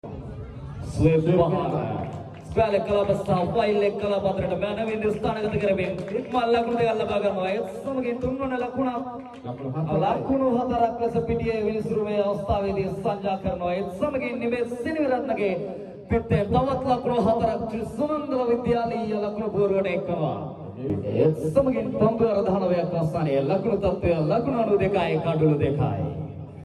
•Commentry එක දුන්නෙ මෙන්න මෙහෙමයි.....🎙♥ sound effects free download